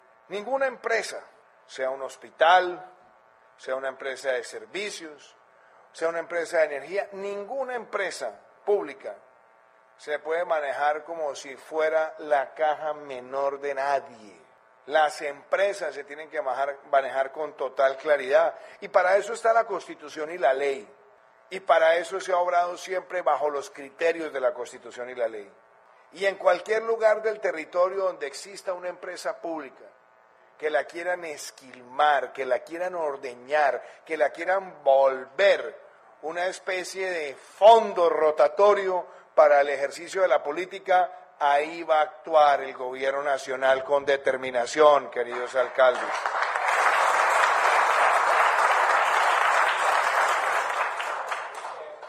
Declaraciones del Presidente de la República, Iván Duque.
Durante el evento de la firma simbólica del ‘Pacto Magdalena’ en el municipio de Aracataca, el presidente, Iván Duque se refirió a la situación de algunas empresas públicas que han tenido malos manejos.